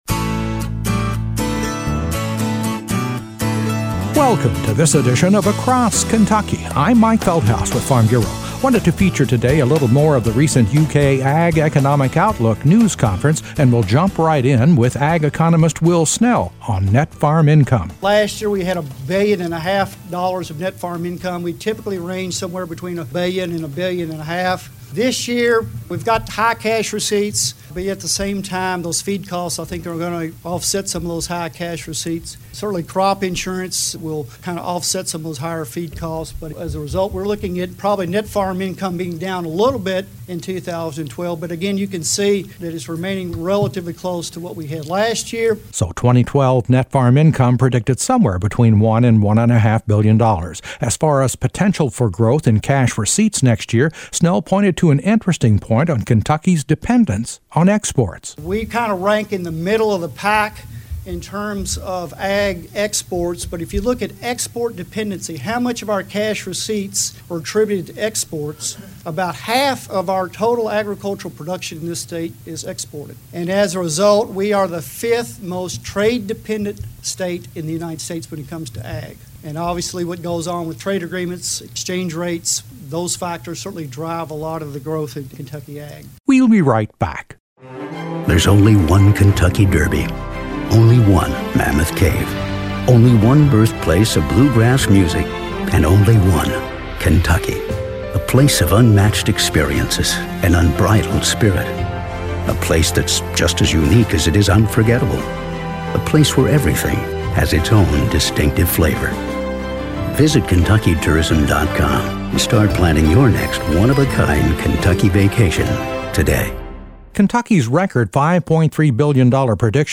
A follow-up report from UK’s Ag Economic Outlook news conference